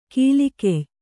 ♪ kīlikey